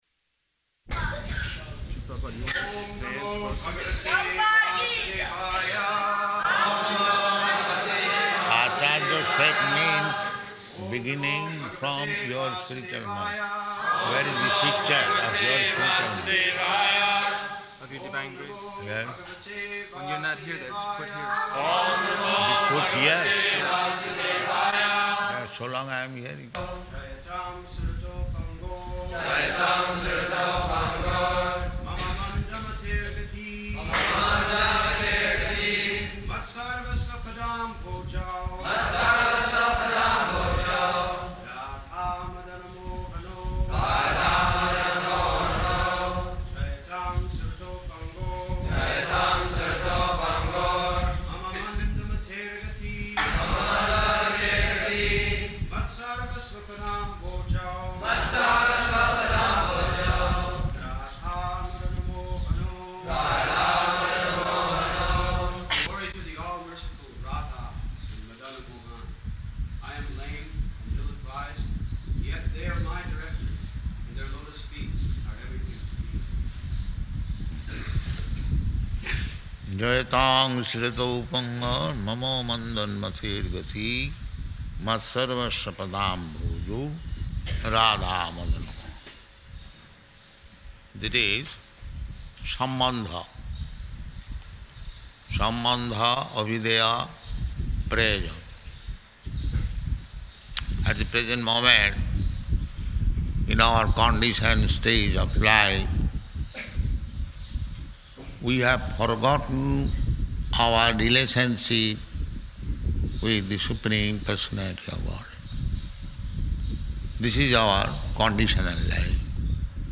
Śrī Caitanya-caritāmṛta, Ādi-līlā 1.15 --:-- --:-- Type: Caitanya-caritamrta Dated: April 8th 1975 Location: Māyāpur Audio file: 750408CC.MAY.mp3 [aside conversation while verse recitation is going on] Devotee (1): Prabhupāda, you want this [indistinct]?